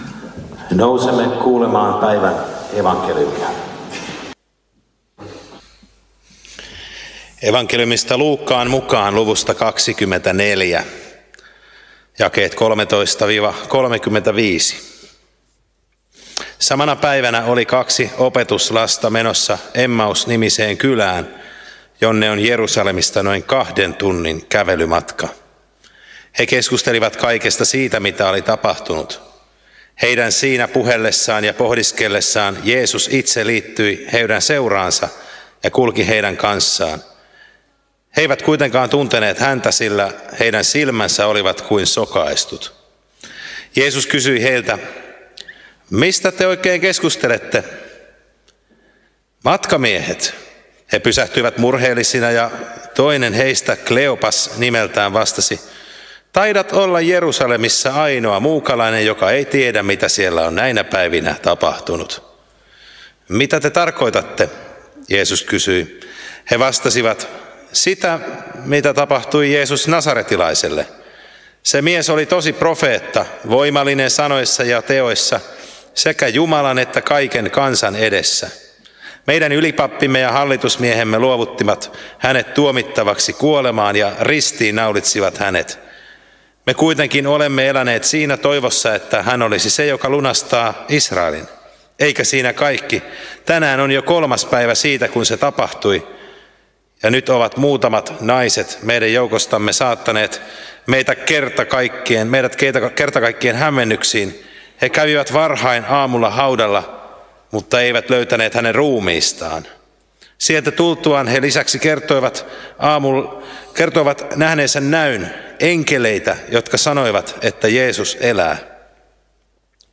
saarna Alajärvellä Tuomasmrëssussa 2. pääsiäispäivänä